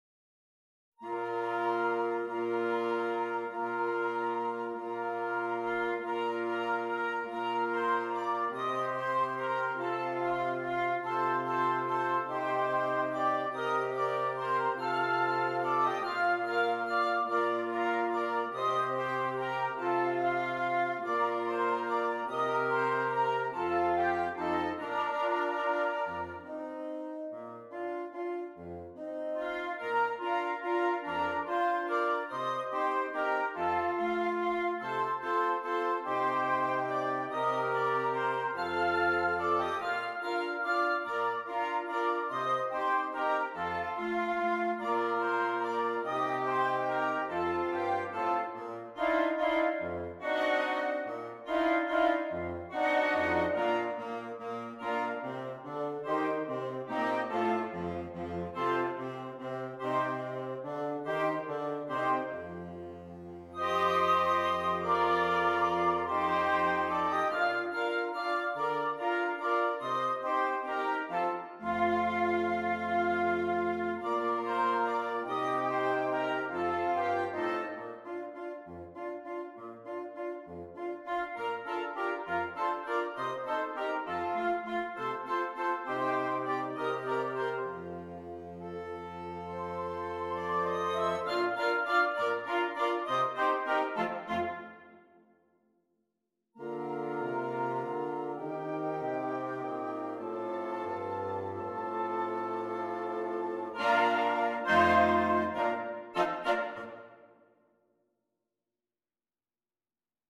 Interchangeable Woodwind Ensemble
Canadian Folk Song